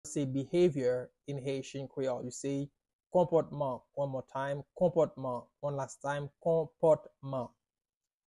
“Behavior” in Haitian Creole – “Konpòtman” pronunciation by a native Haitian tutor
“Konpòtman” Pronunciation in Haitian Creole by a native Haitian can be heard in the audio here or in the video below:
How-to-say-Behavior-in-Haitian-Creole-–-Konpotman-pronunciation-by-a-native-Haitian-tutor.mp3